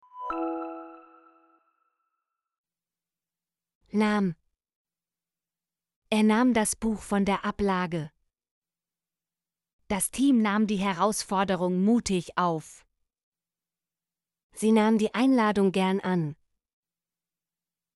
nahm - Example Sentences & Pronunciation, German Frequency List